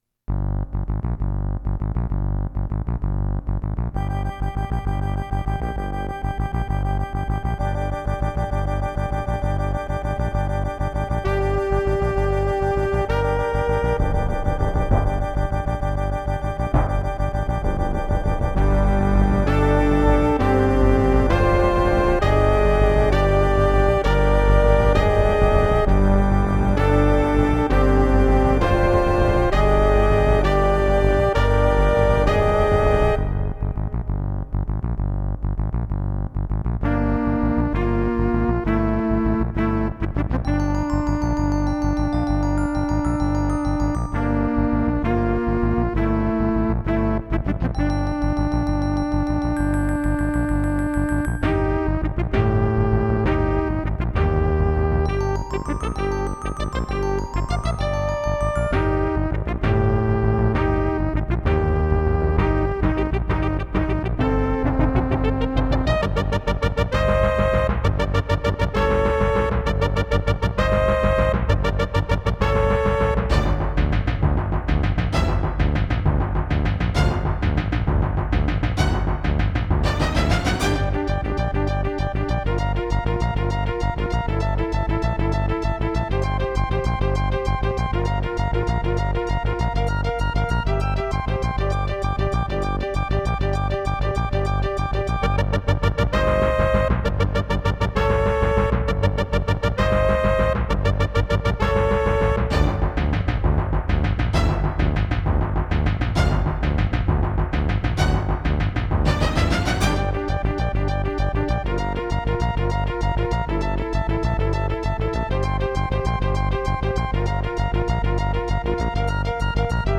As recorded from the original Roland MT-32 score